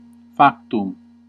Ääntäminen
IPA : /diːd/ US : IPA : [diːd]